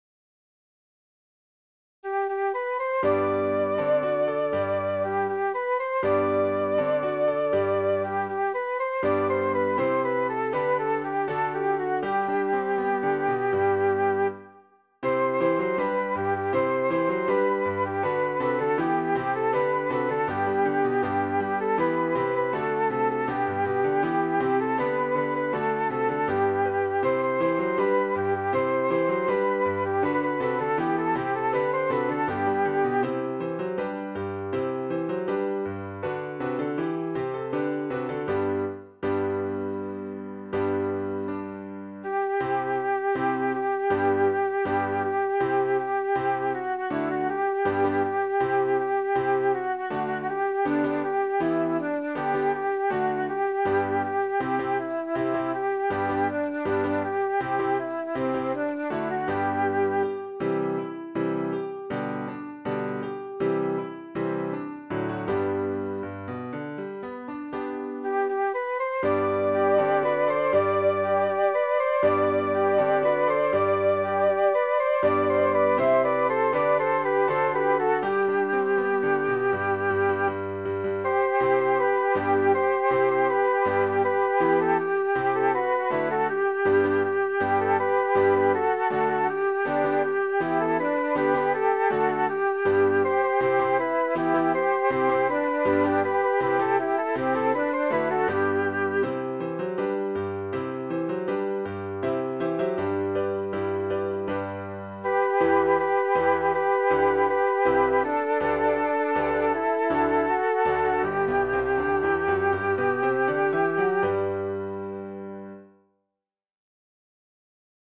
• A introdución, e máis a súa reaparición a dúas voces no comezo da segunda parte, báilanse libremente.
• Observa que cada frase consta de oito pulsos (dous compases de 4/4); coida de ir contando sempre os pulsos para axusta-los movementos coa música.